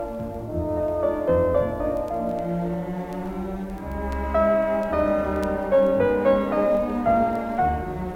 ピアノ
ノイズを取り除いたあとの波形の音声が聴けます。おおきなノイズは消えました。
ノイズ1から7を処理したあとのサンプルの音